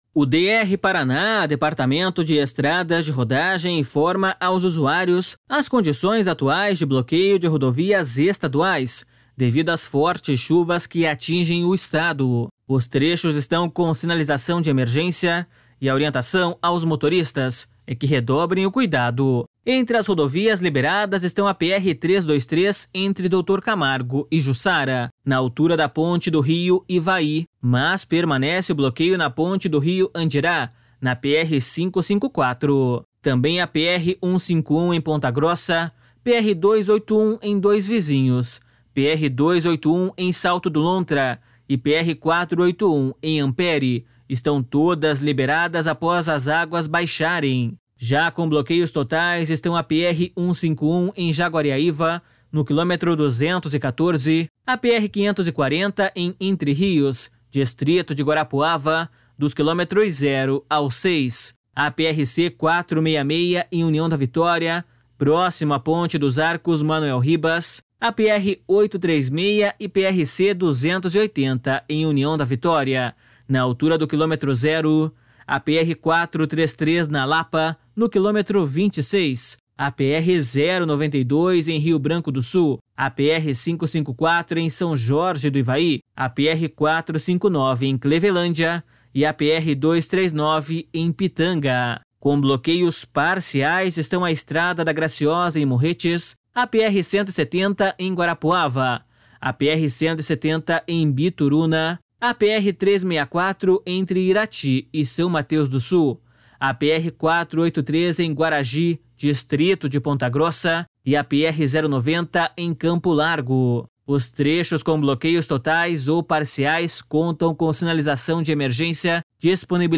BOLETIM RODOVIAS ESTADUAIS 02-11.mp3